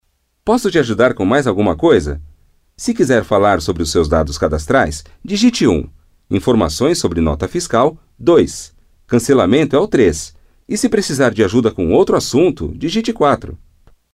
Portugais (Brésil)
Commerciale, Naturelle, Fiable, Amicale, Corporative
Téléphonie